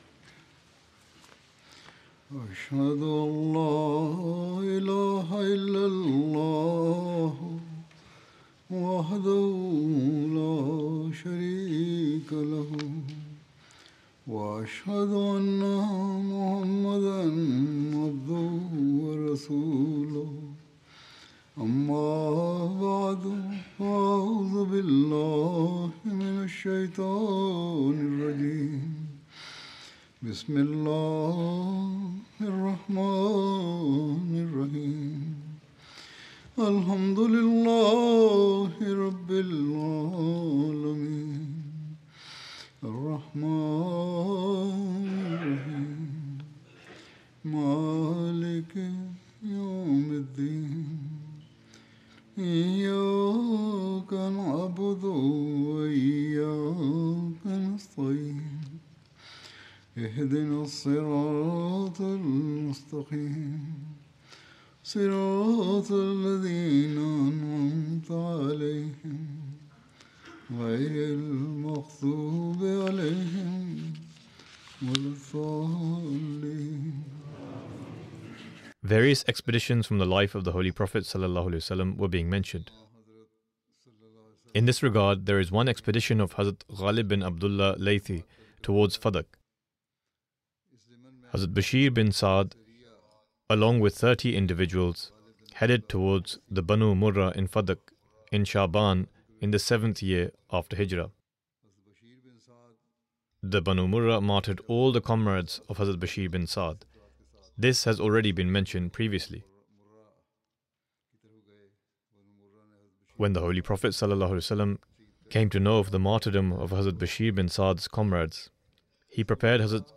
English translation of Friday Sermon delivered by Khalifa-tul-Masih on February 7th, 2025 (audio)